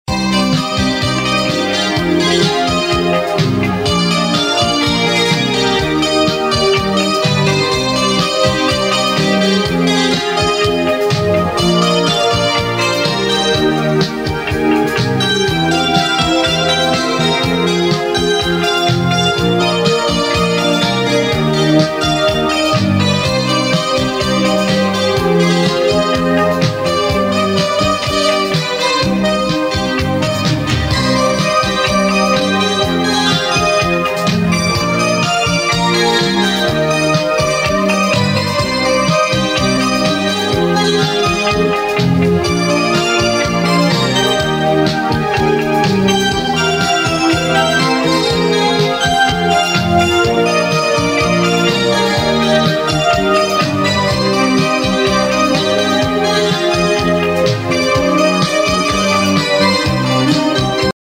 • Качество: 320, Stereo
спокойные
без слов
инструментальные
80-е
Фоновая музыка прогноза погоды